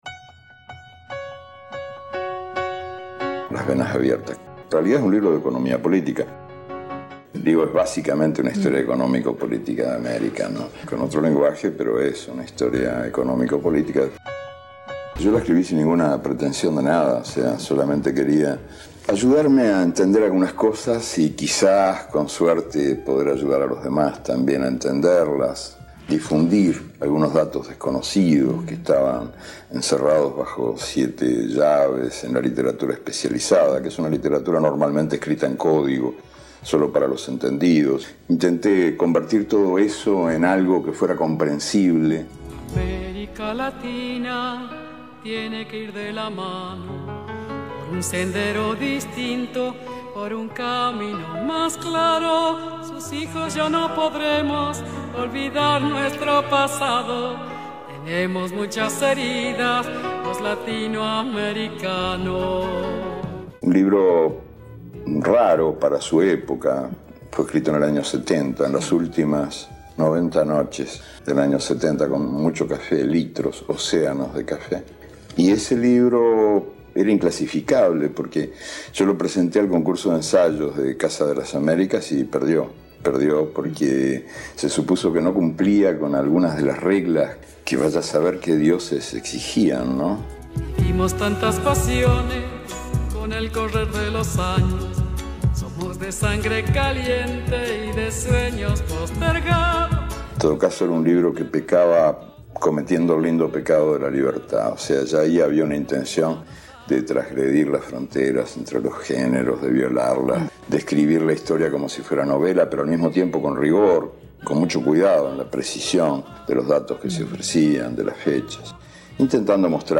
Eduardo Galeano parla de Le vene aperte dell’America Latina